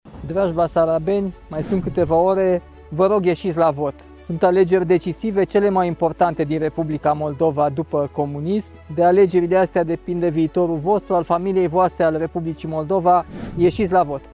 Nicușor Dan, mesaj pentru basarabeni: „Vă rog să ieșiți la vot. Sunt alegeri decisive, cele mai importante din Republica Moldova după comunism” : Europa FM
28sept-18-Nicusor-hai-la-vot.mp3